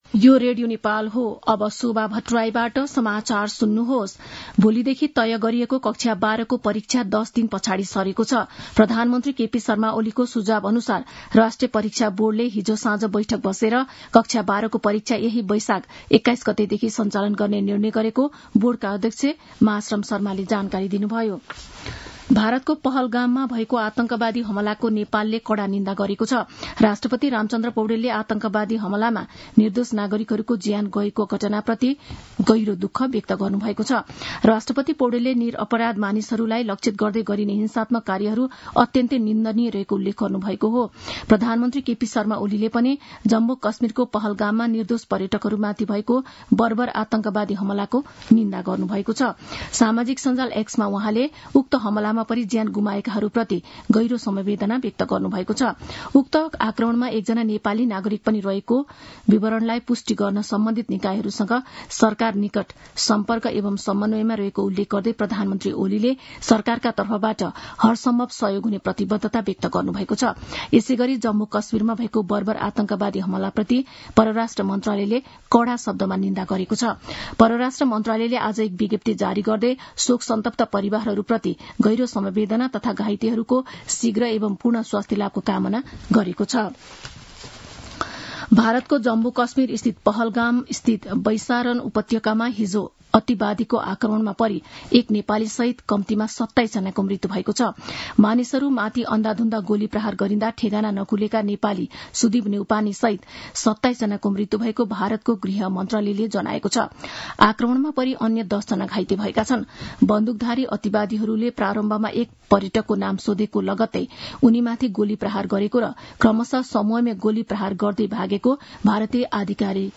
मध्यान्ह १२ बजेको नेपाली समाचार : १० वैशाख , २०८२